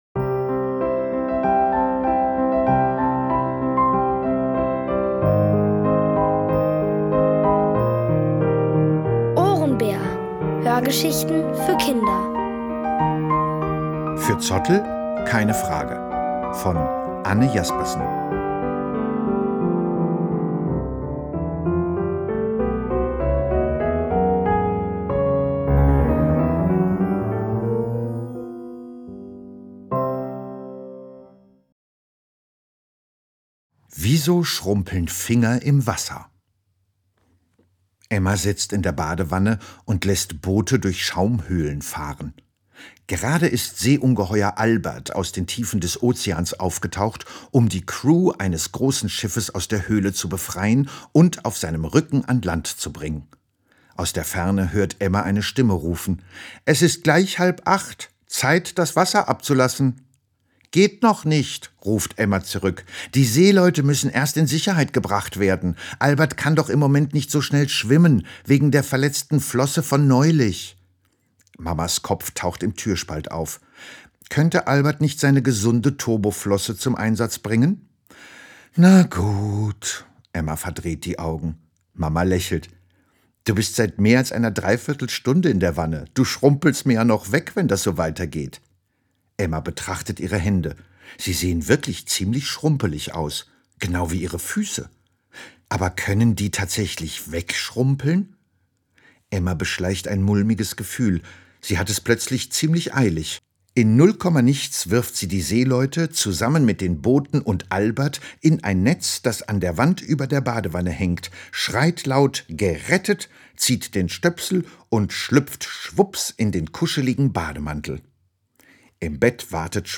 Von Autoren extra für die Reihe geschrieben und von bekannten Schauspielern gelesen.
Es liest: Bernd Moss.